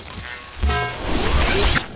bsword1.ogg